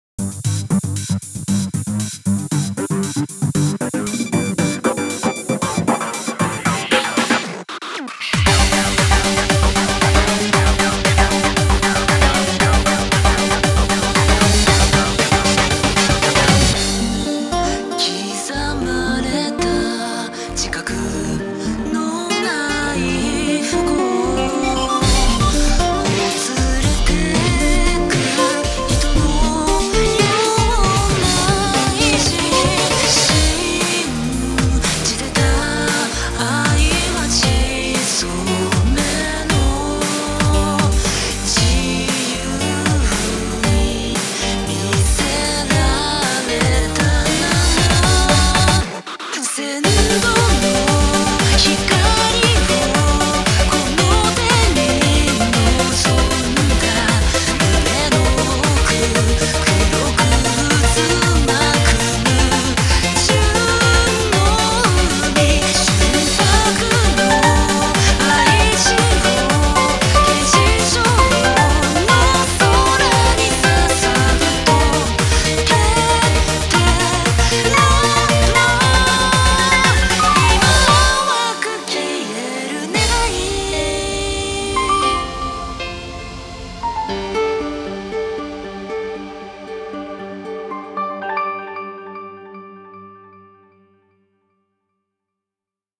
BPM116
Audio QualityPerfect (High Quality)
Genre: J-FUTURE TECHNOPOP.